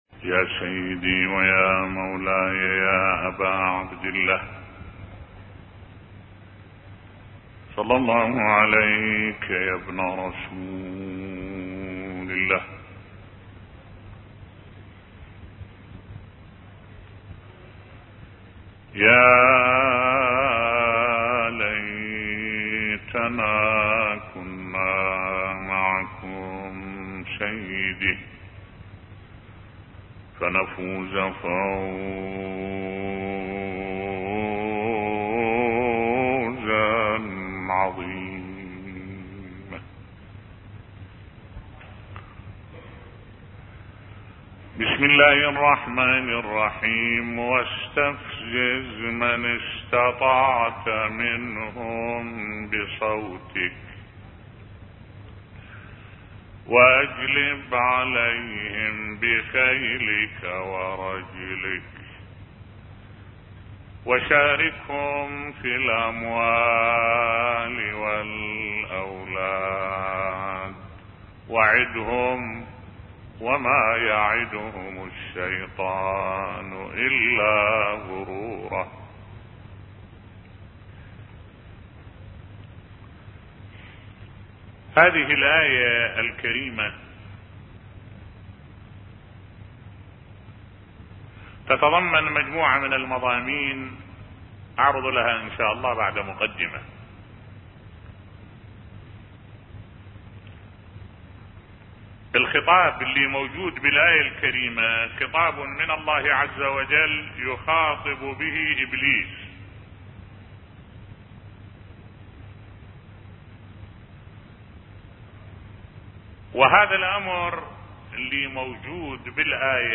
ملف صوتی واستفزز من استطعت منهم بصوتك بصوت الشيخ الدكتور أحمد الوائلي